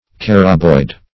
Search Result for " caraboid" : The Collaborative International Dictionary of English v.0.48: Caraboid \Car"a*boid\ (k[a^]r"[.a]*boid), a. [Carabus + -oid.]